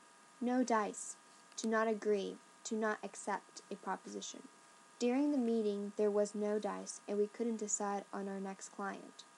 英語ネイティブによる発音はこちら下記のリンクをクリックしてください。